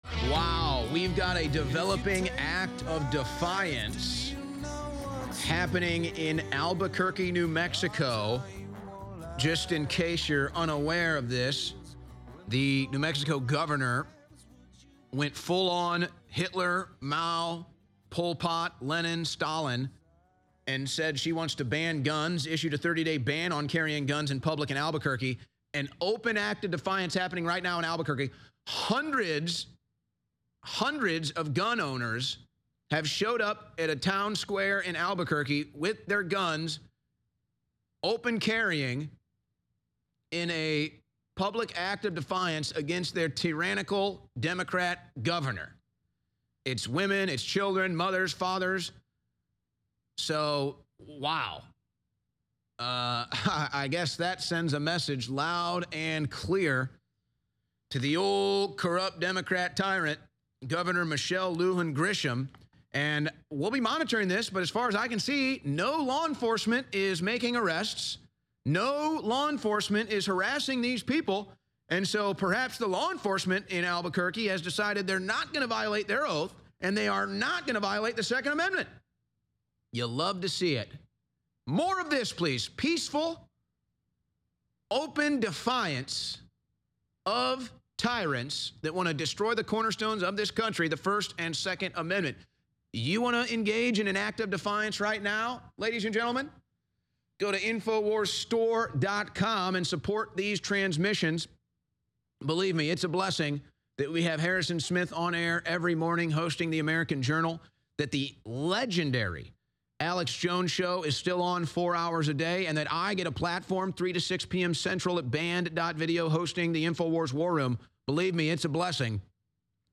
Albuquerque Residents Openly Defy Governor’s Attack On 2nd Amendment By Open Carrying At Peaceful Rally In Town Square